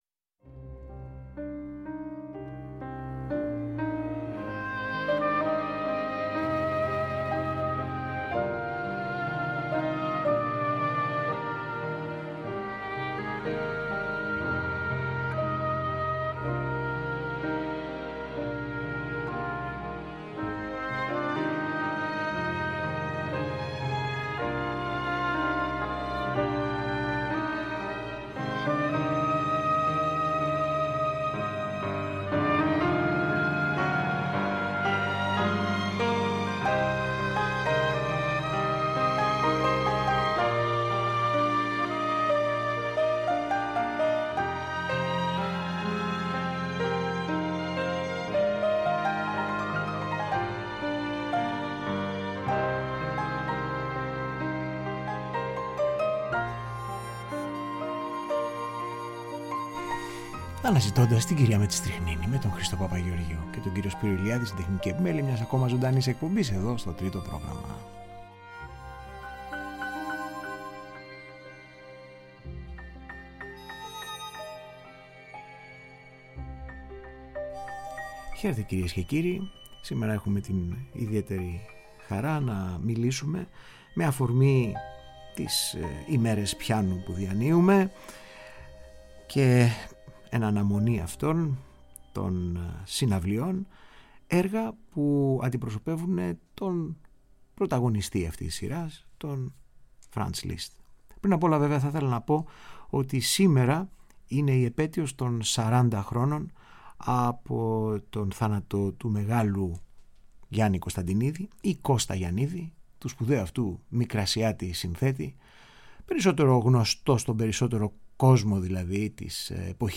Μεταγραφές έργων του Franz Liszt από άλλους – κυρίως Τζαζ- συνθέτες.